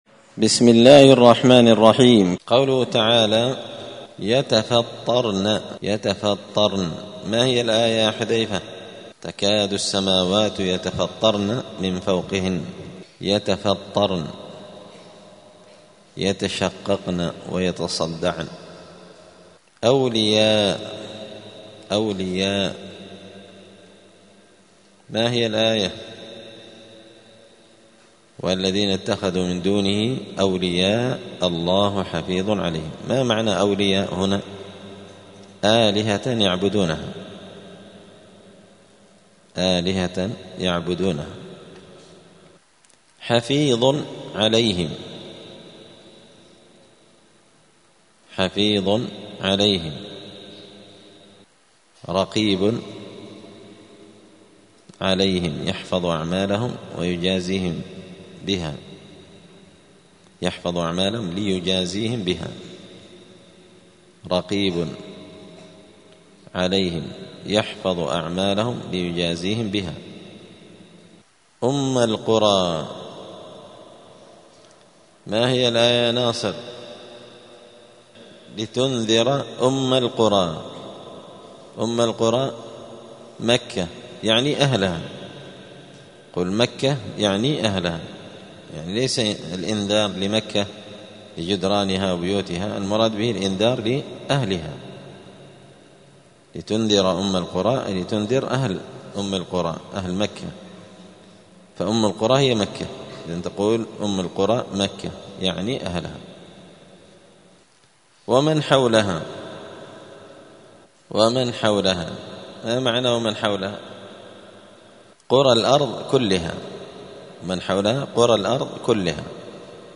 *(جزء فصلت سورة الشورى الدرس 224)*